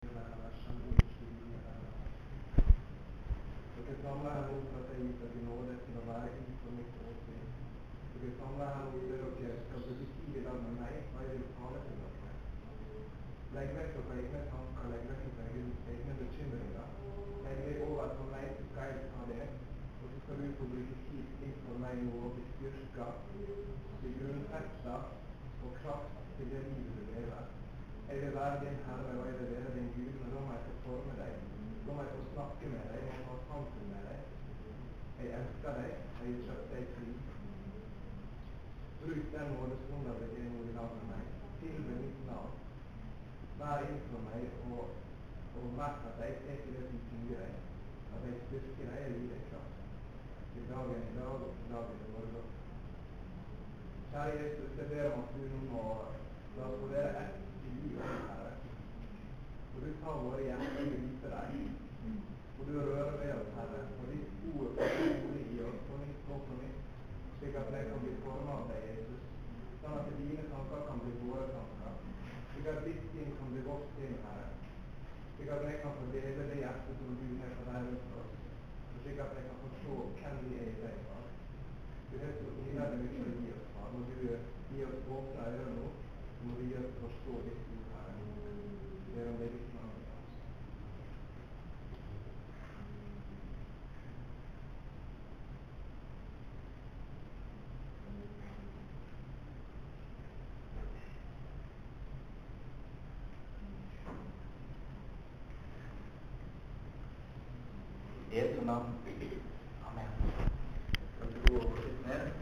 Ebeneser Volda, søndag 7.10.07. kl19
Ein mann kom med denne bodskapen frå Gud: